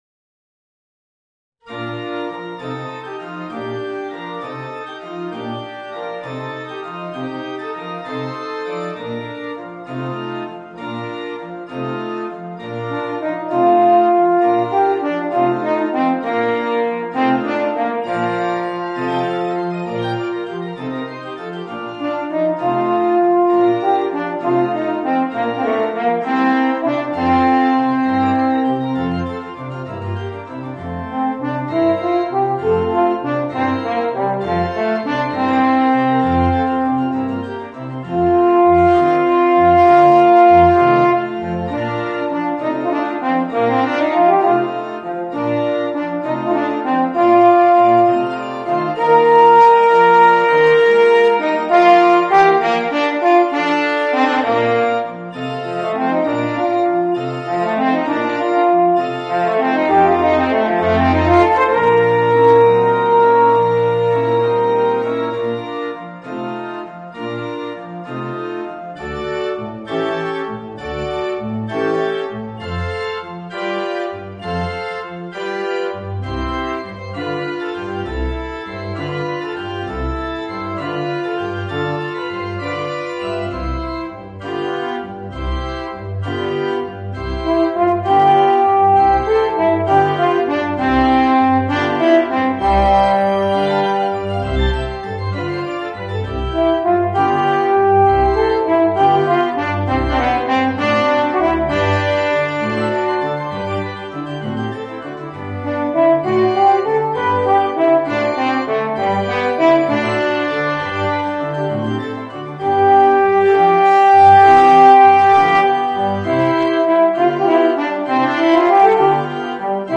Voicing: Eb Horn and Organ